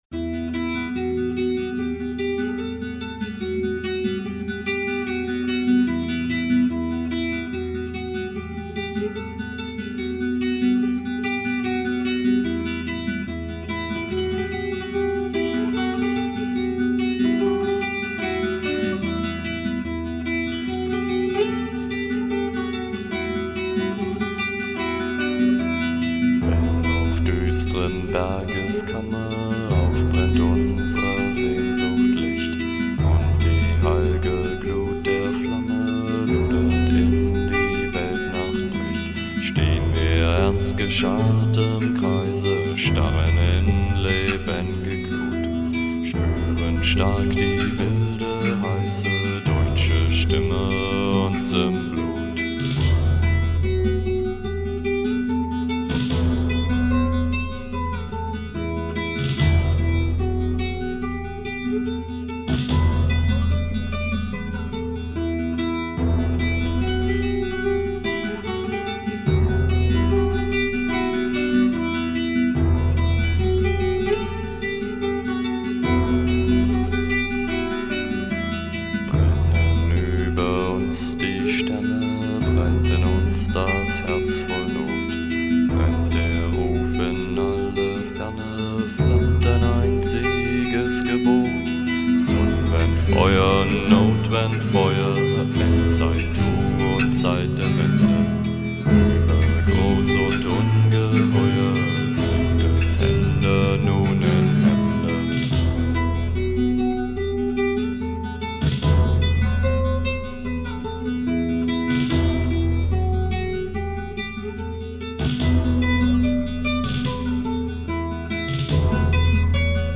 Стиль: Dark Folk